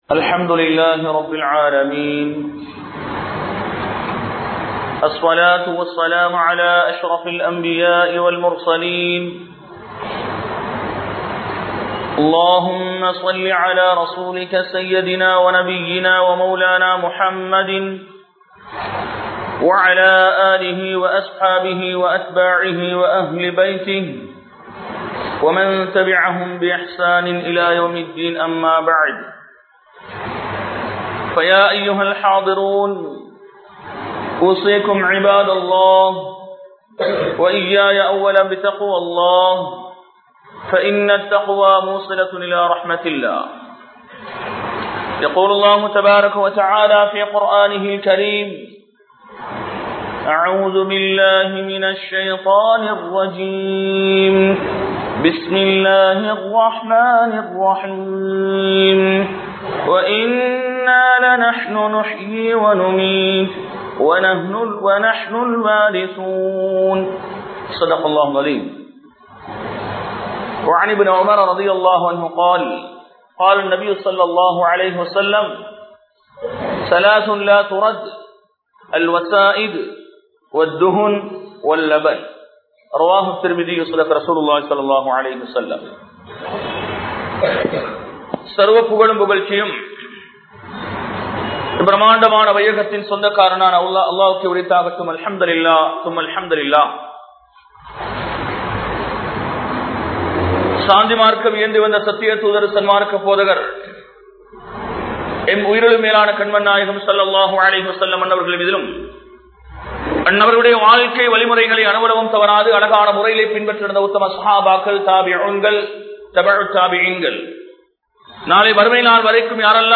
Nankodai (நன்கொடை) | Audio Bayans | All Ceylon Muslim Youth Community | Addalaichenai